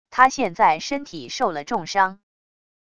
他现在身体受了重伤wav音频生成系统WAV Audio Player